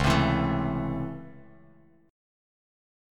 DbmM7#5 chord